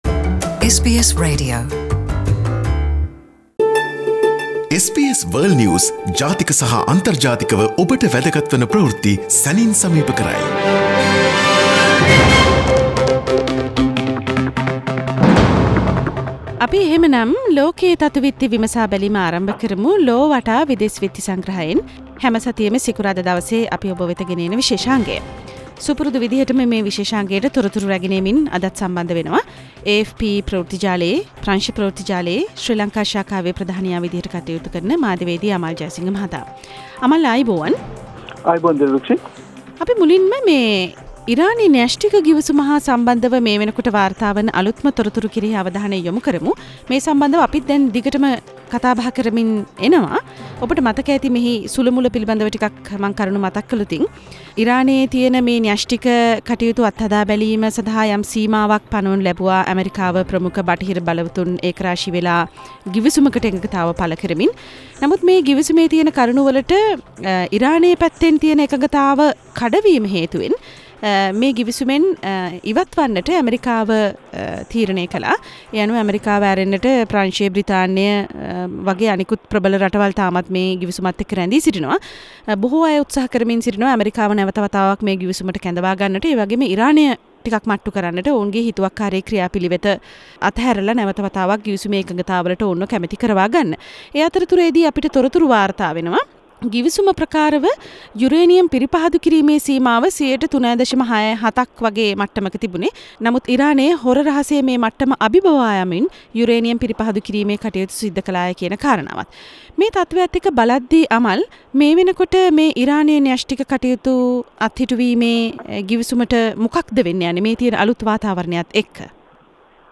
විදෙස් විත්ති සමාලෝචනය